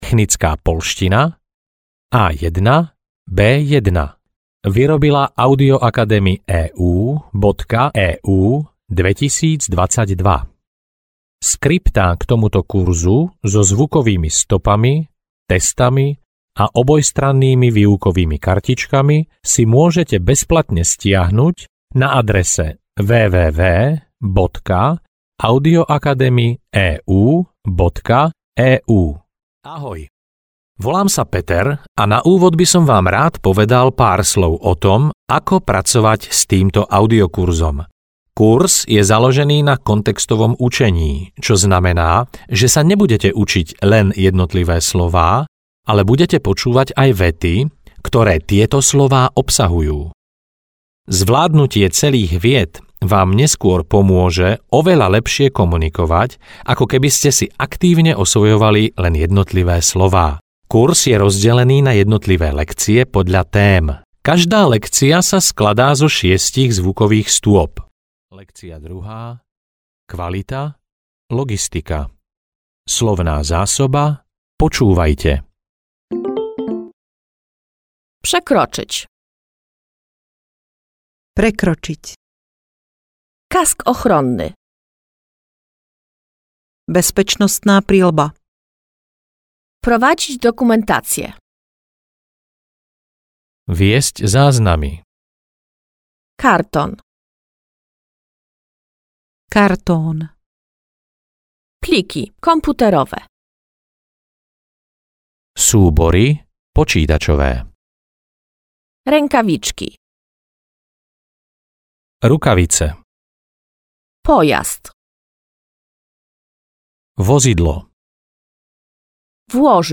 Ukázka z knihy
Keď zvládnete preklad viet zo slovenčiny do poľštiny (lekcia 6) v časovej medzere pred poľským prekladom, máte vyhraté.